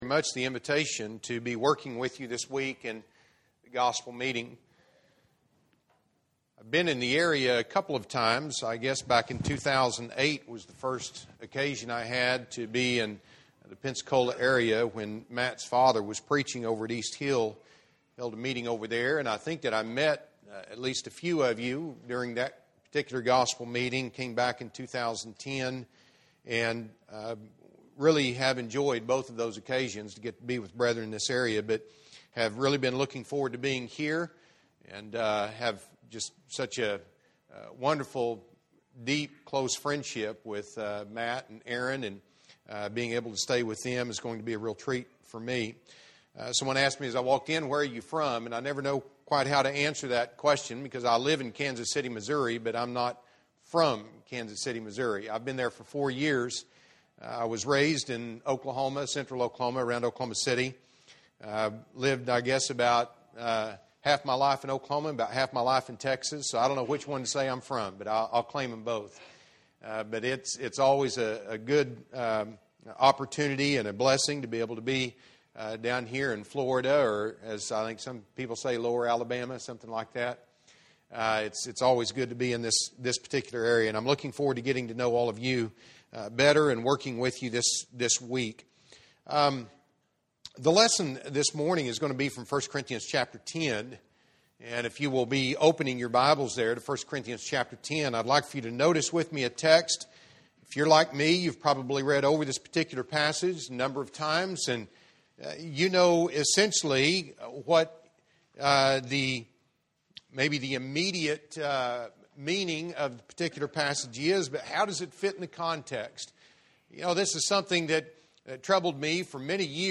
Service Type: Gospel Meeting